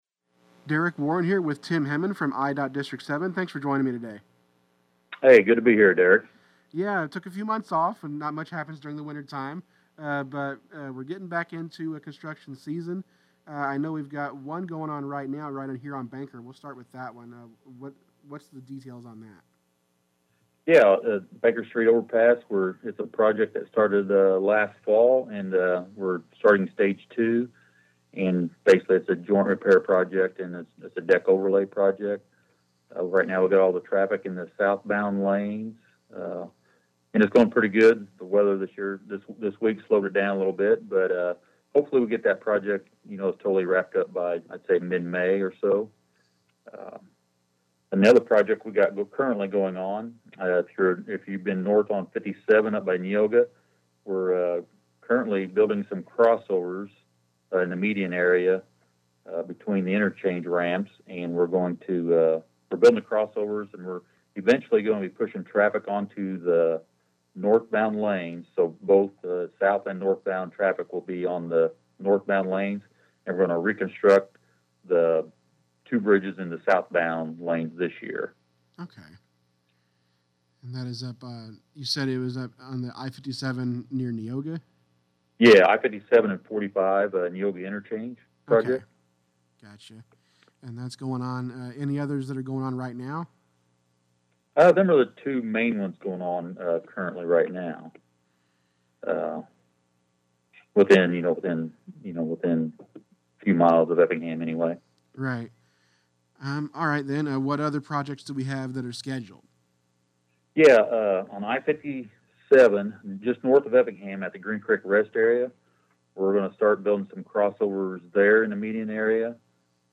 idot-interview-3-5-26.mp3